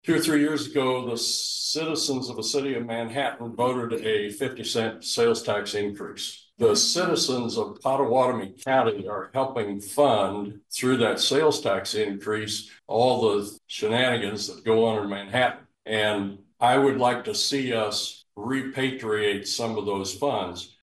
A Pottawatomie County Planning Board member also spoke during public comment, noting he is supportive of the county exploring another sales tax question on the November ballot to address infrastructure needs.